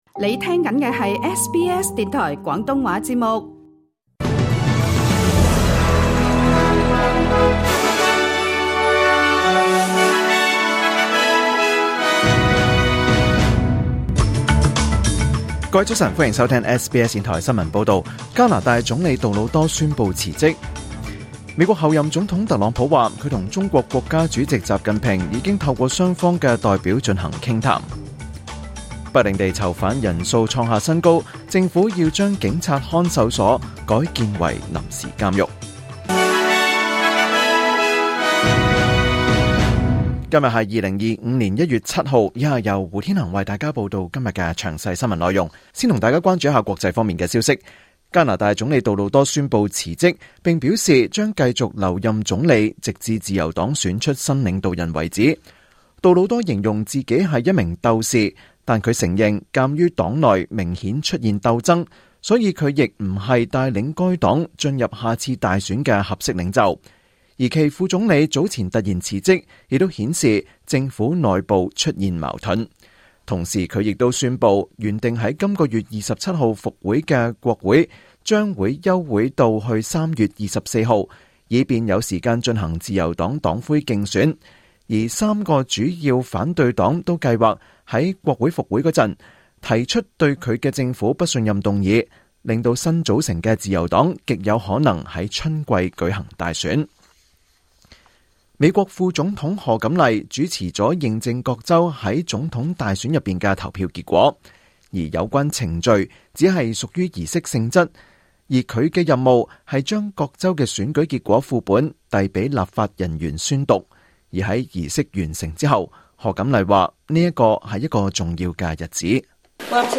2025年1月7日SBS廣東話節目詳盡早晨新聞報道。